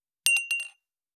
285,カチャ,ガチャン,カタッ,コトン,ガシャーン,カラン,カタカタ,チーン,
コップ効果音厨房/台所/レストラン/kitchen食器
コップ